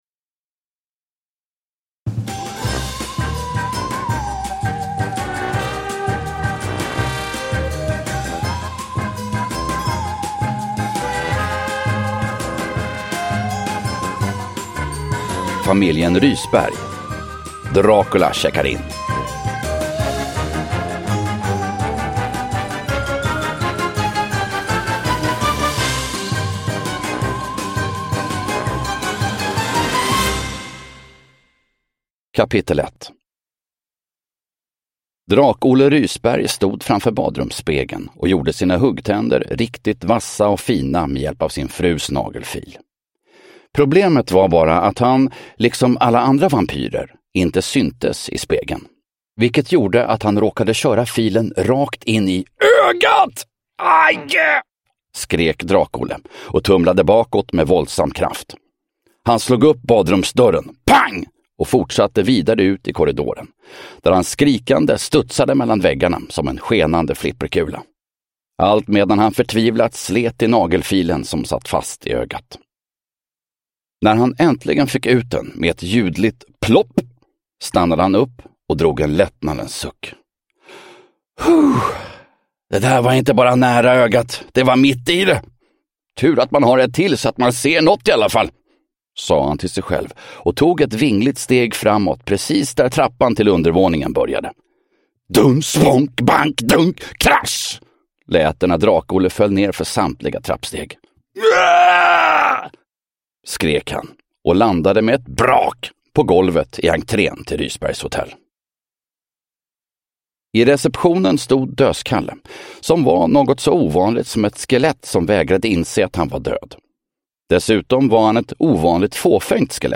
Dracula checkar in – Ljudbok
Uppläsare: Fredde Granberg